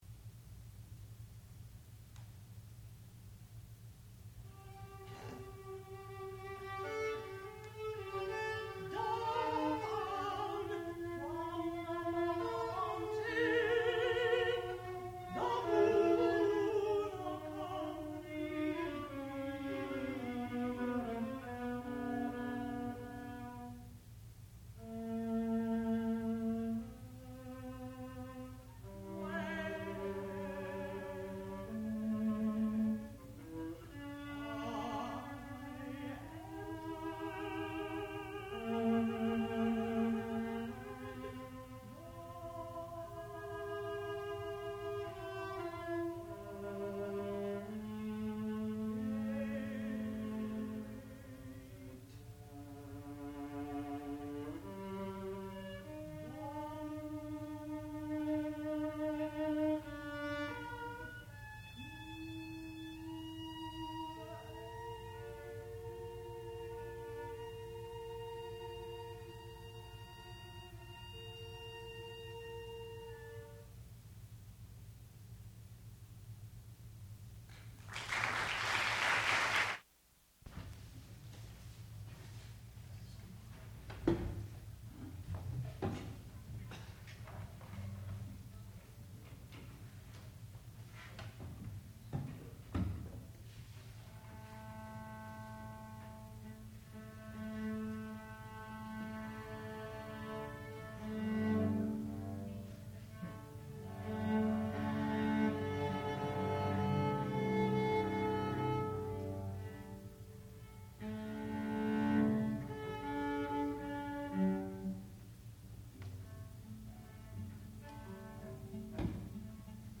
sound recording-musical
classical music
mezzo-soprano
viola